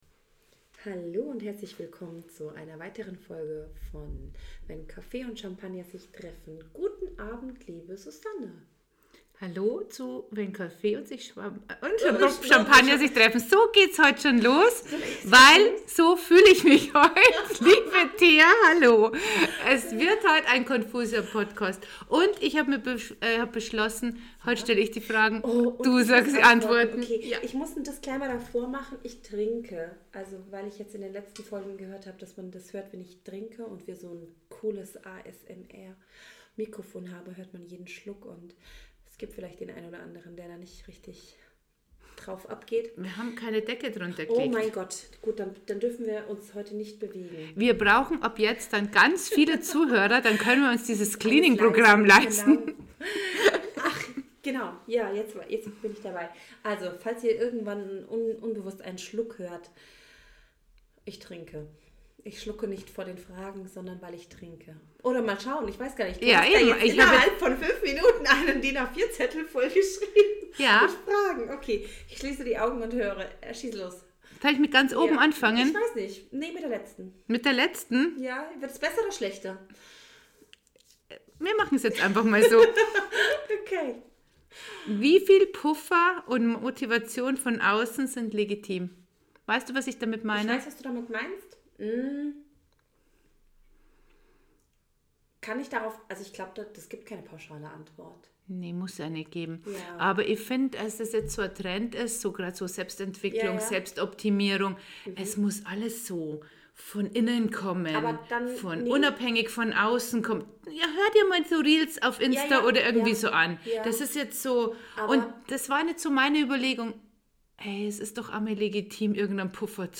Zwei Frauen die dich in ihre Gedanken mitnehmen.